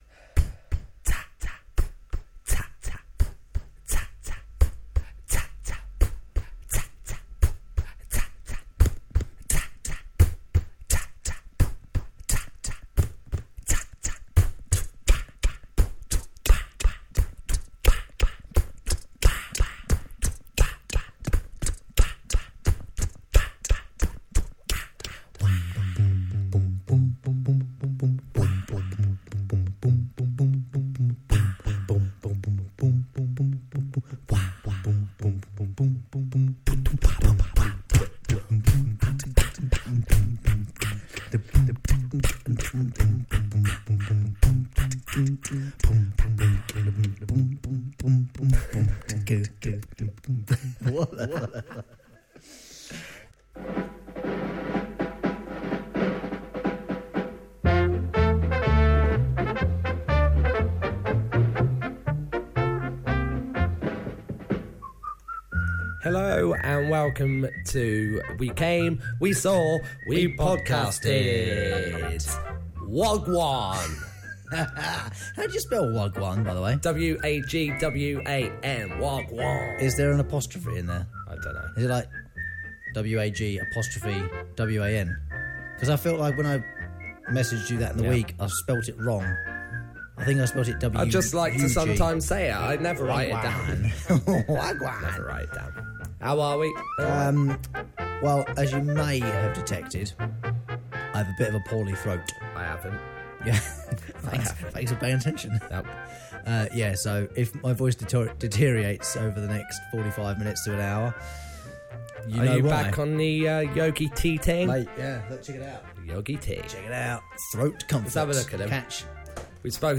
Who would have guessed we could Beatbox?!